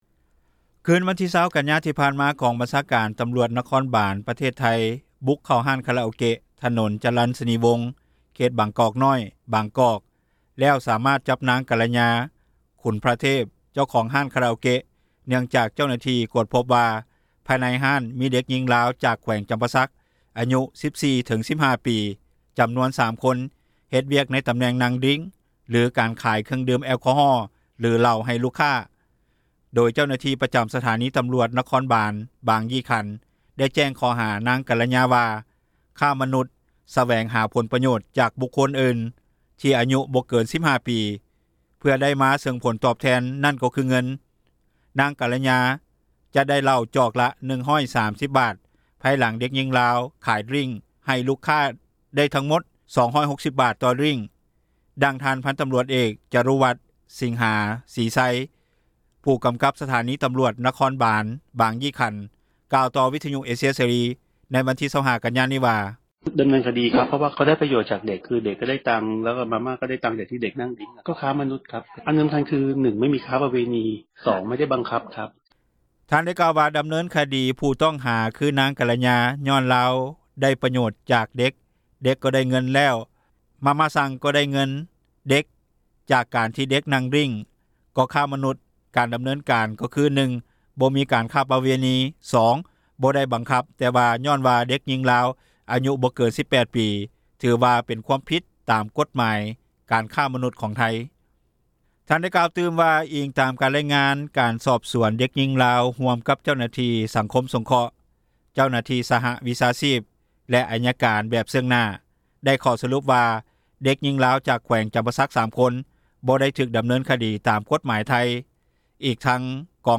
ຕຳຣວດໄທຍ ບຸກຄົ້ນຮ້ານ ພົບເດັກນ້ອຍຍິງລາວ ອາຍຸ 14-15 ປີ — ຂ່າວລາວ ວິທຍຸເອເຊັຽເສຣີ ພາສາລາວ